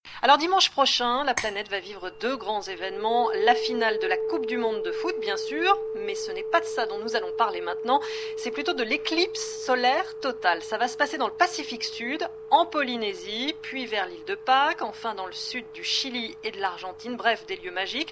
voix.mp3